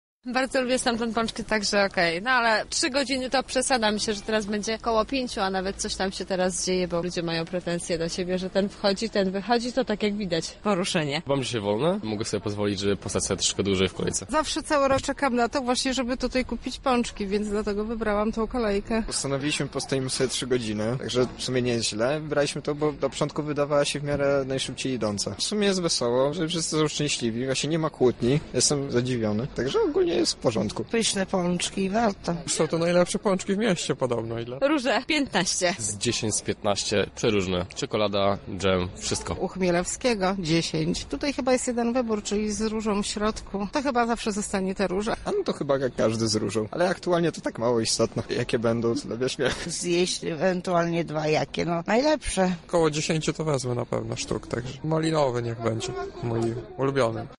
Z tego powodu spytaliśmy mieszkańców naszego miasta stojących w kolejkach na deptaku dlaczego wybrali słodkości tradycyjne, a nie te z marketu oraz ile i jakich pączków mają zamiar zjeść.
Sonda